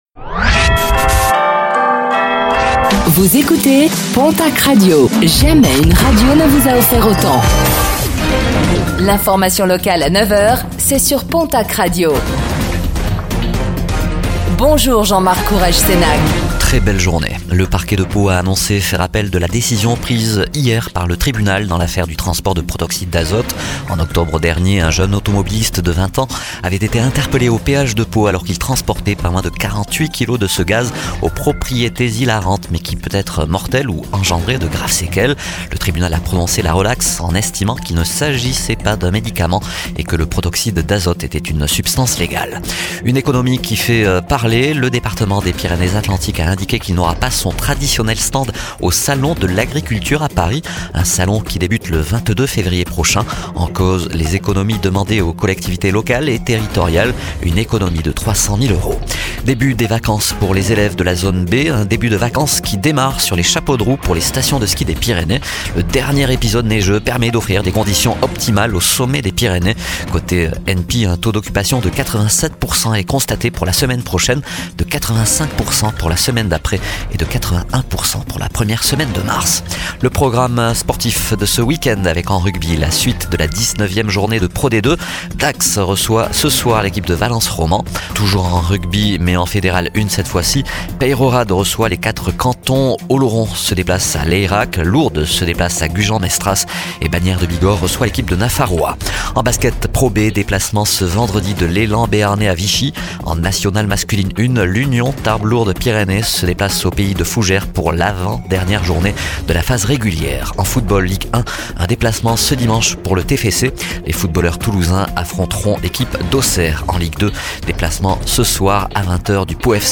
Réécoutez le flash d'information locale de ce vendredi 07 février 2025